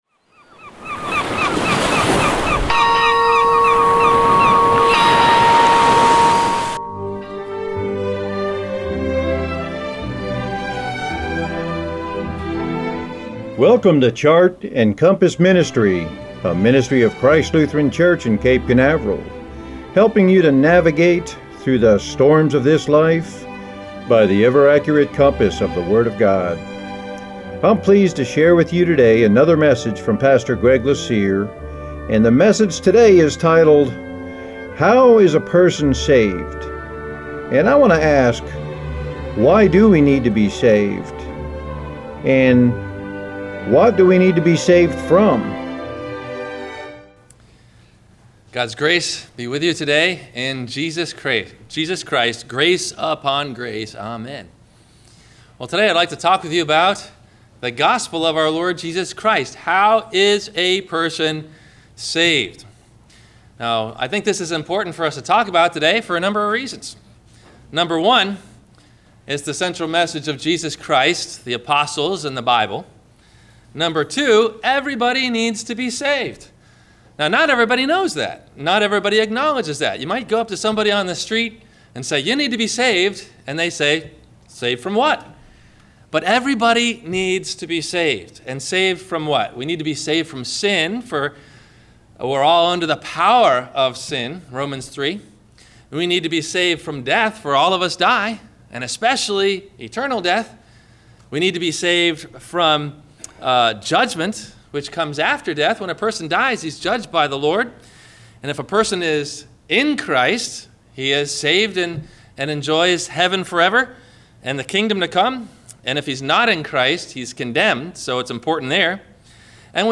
How is a Person Saved? – WMIE Radio Sermon – February 15 2016